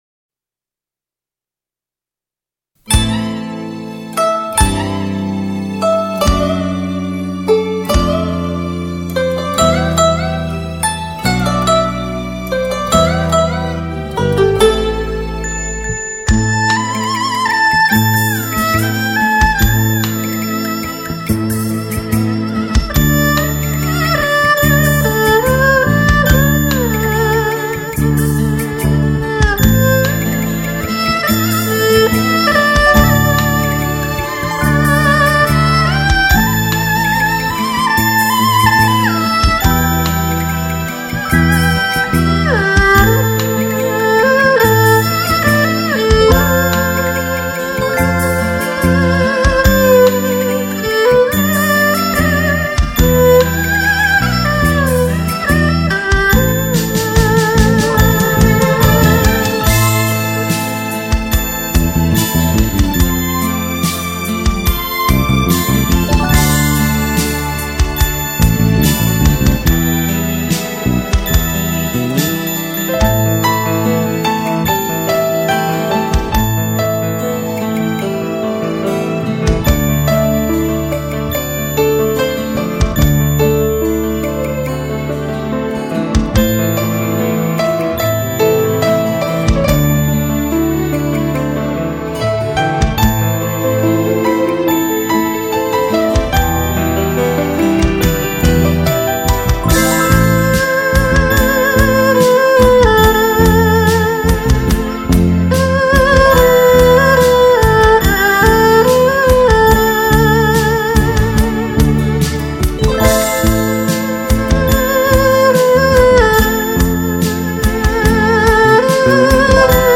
专辑格式：DTS-CD-5.1声道
二胡